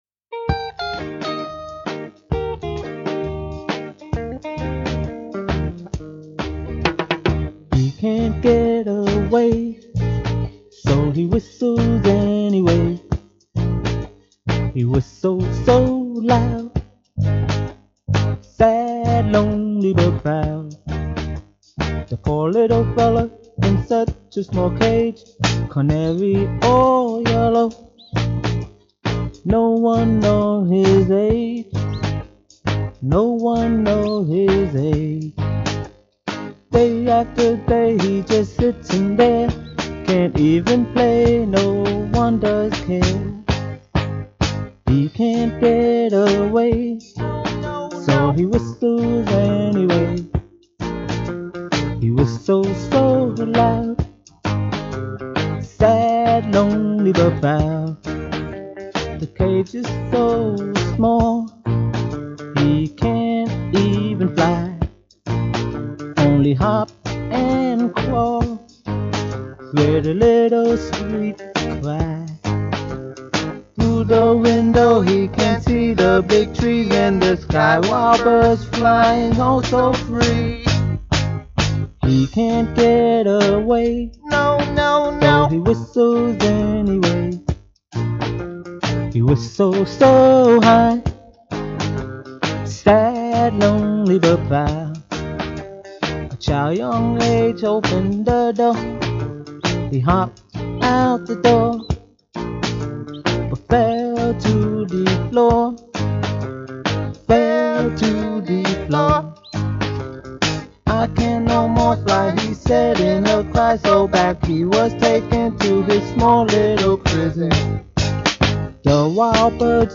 Enregistré à Valotte